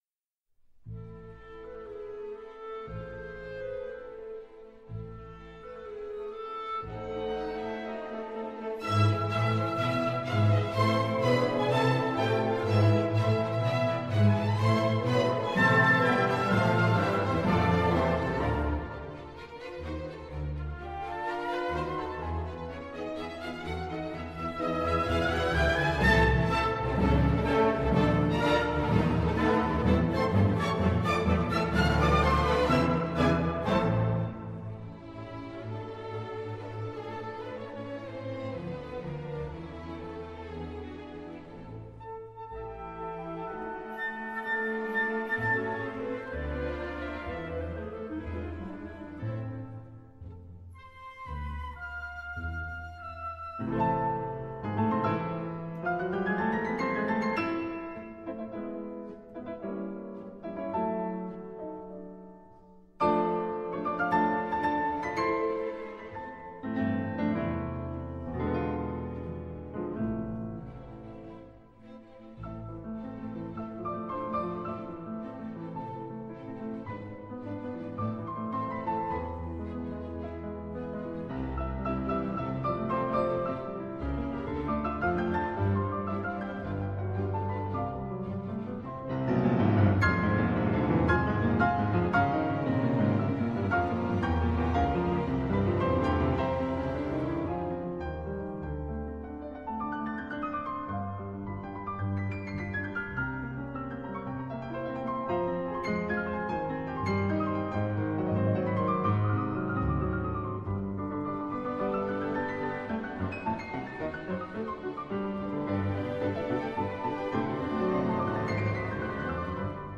موسیقی کلاسیک: کنسرتو پیانو از شارل آلکان آهنگساز فرانسوی - Charles Valentin Alkan - 3 Concerti da camera Op10